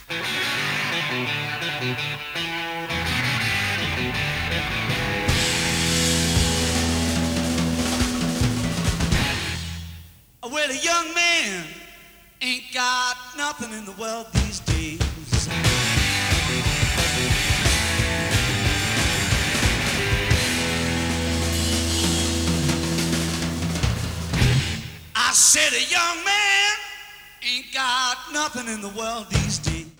Format/Rating/Source: CD - A - Soundboard